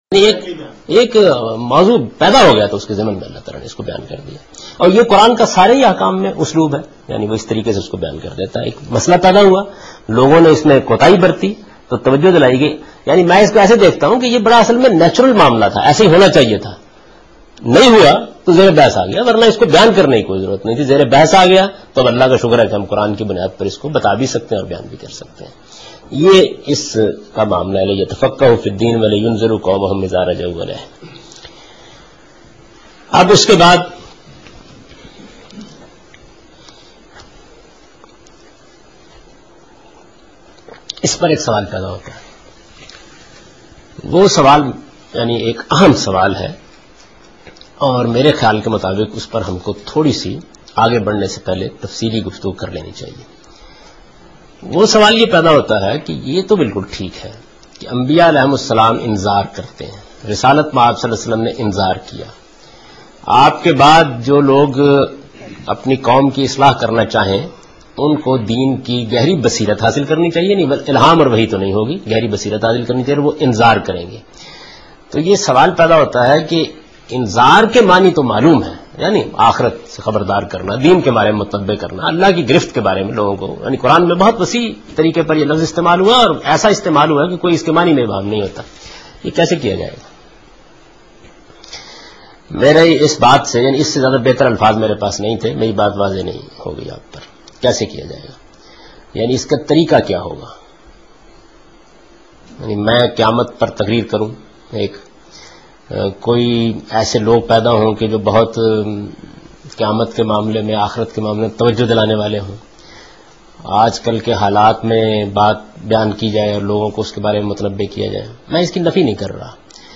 Javed Ahmed Ghamidi: From Meezan Lectures